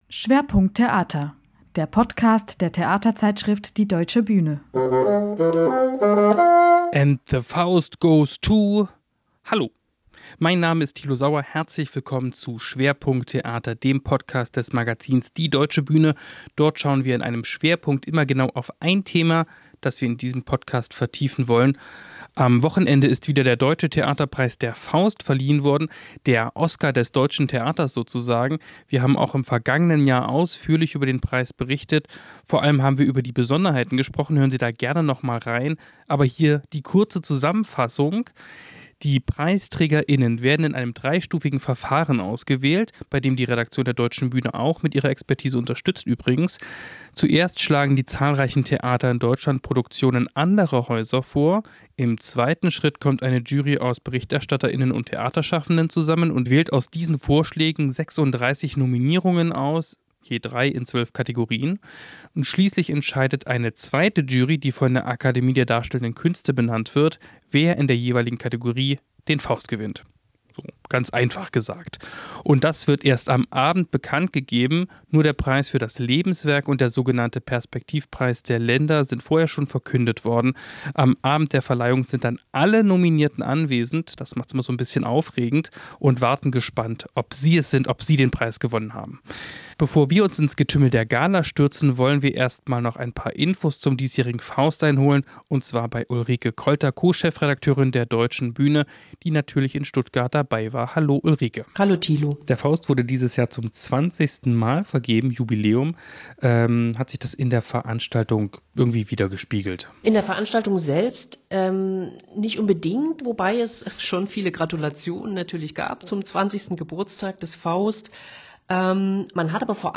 Party-O-Töne von Nominierten und Preisträger:innen